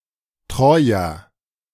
Als Troyer (auch Troier; IPA: [ˈtʁɔɪ̯ɐ],